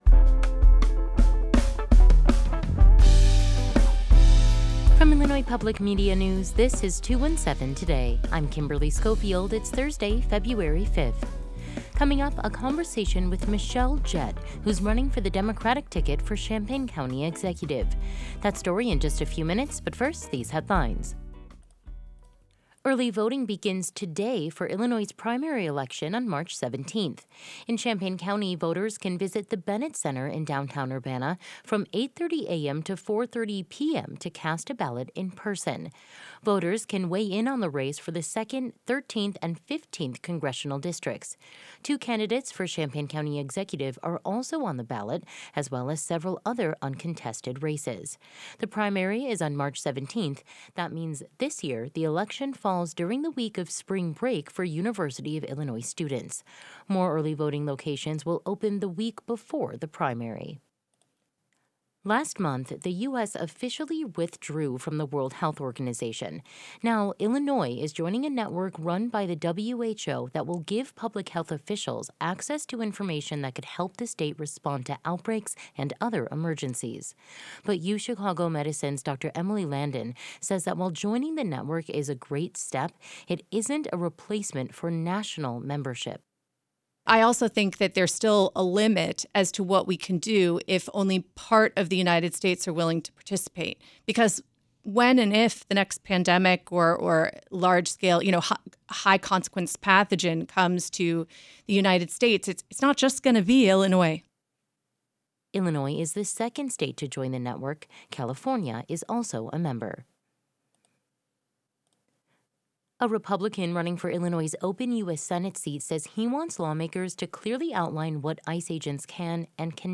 Today’s headlines: Early voting begins today for Illinois' primary election on March 17th. Last month, the U.S. officially withdrew from the World Health Organization.